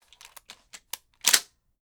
Bullet In.wav